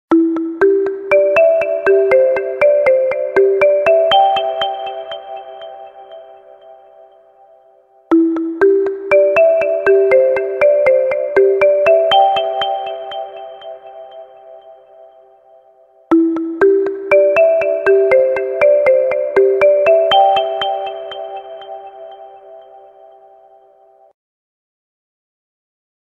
эхо
звонкие